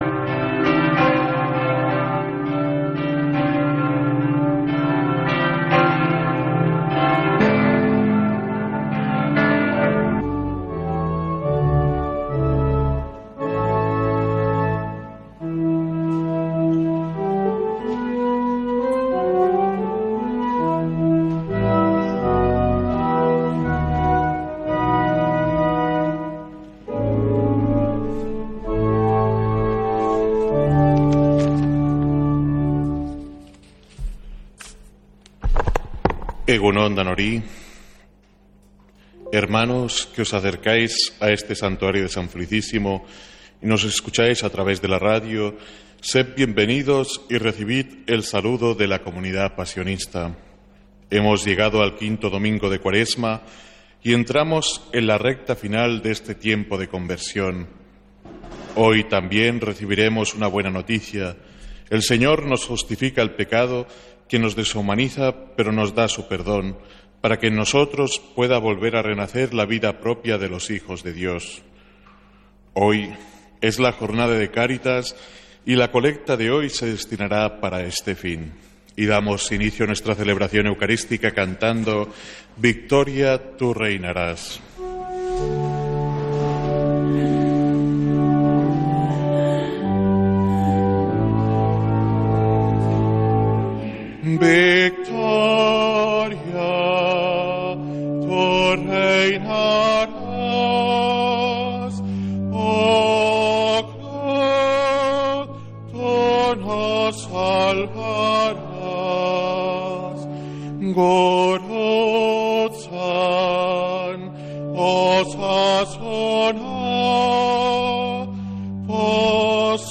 Santa Misa desde San Felicísimo en Deusto, domingo 6 de abril